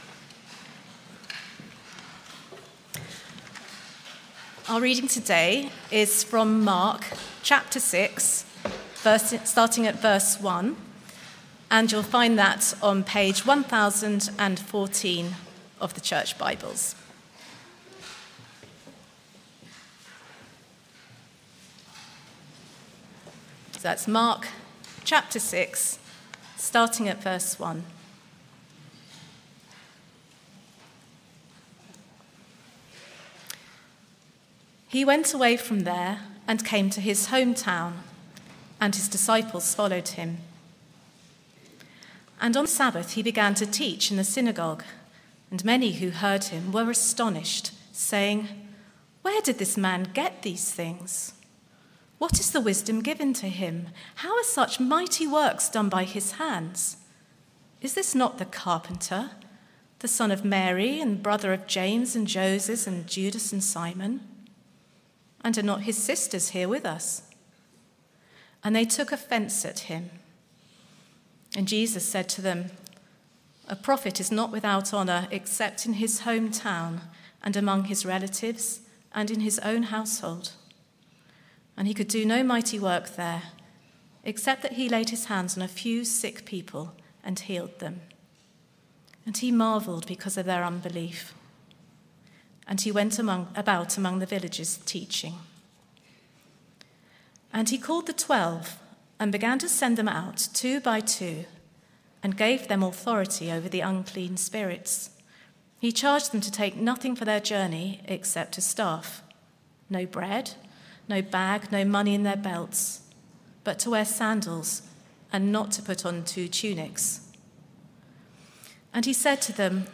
Media for Morning Meeting on Sun 24th Nov 2024 10:30 Speaker
Mark 1-6 Sermon - Audio Only Search media library...